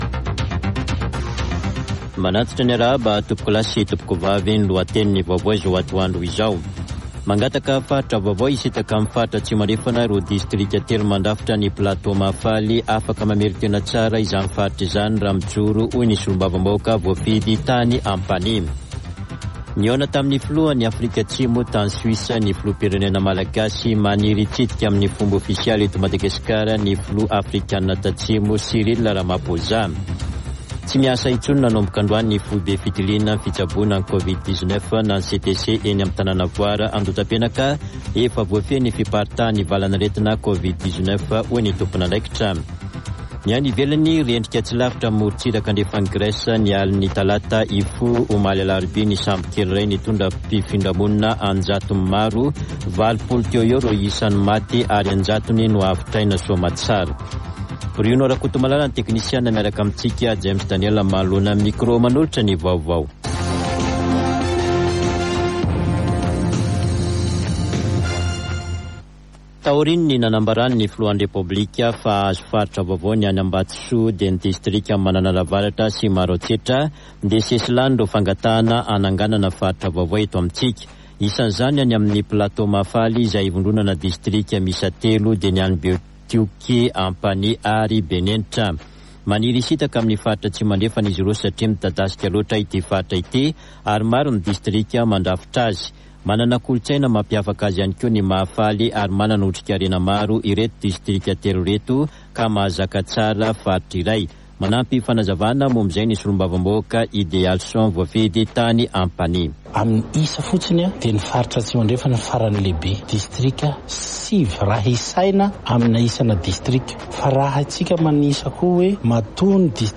[Vaovao antoandro] Alakamisy 15 jona 2023